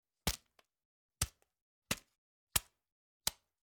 Звук Втыкаем нож в банан несколько раз (прямо в кожуру) (00:04)